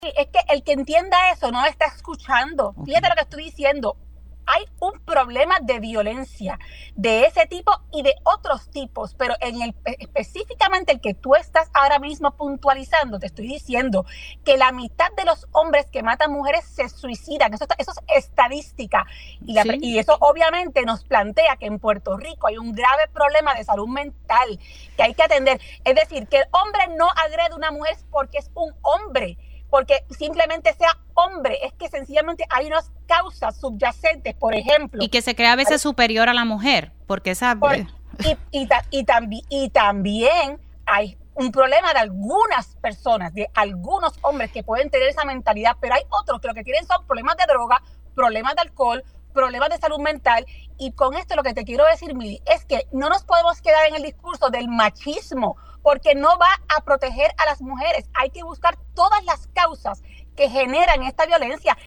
A través de una video llamada en Pega’os en la Mañana, hoy, viernes,  Rodríguez Veve quien está involucrada en un caso bajo investigación por parte de la Oficina de la Procuradora de la Mujer, comunicó que es importante “defender la vida humana y respetarla en todo momento”.